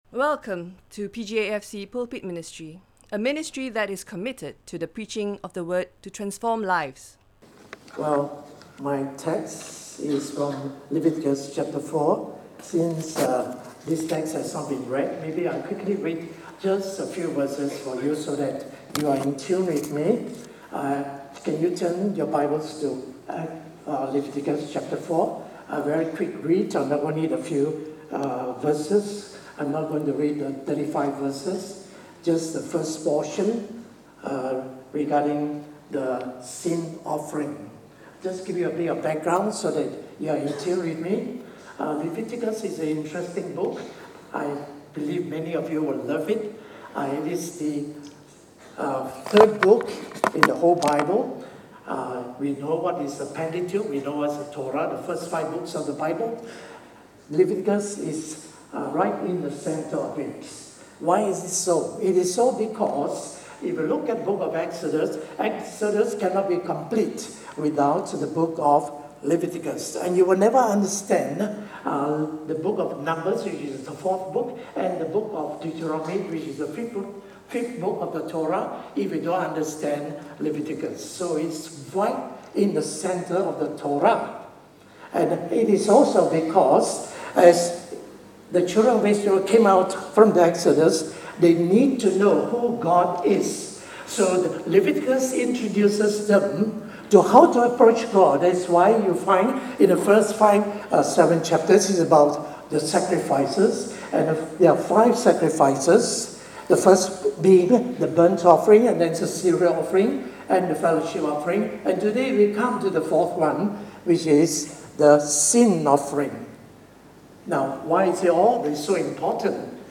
This is a stand alone sermon.